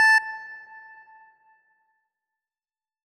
A3.wav